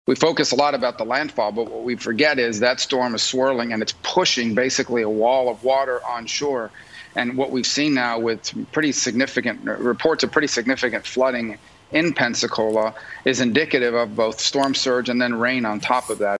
Florida Republican Senator Marco Rubio tells “Fox and Friends” his biggest concern with Sally is the flooding, as parts of Pensacola are underwater: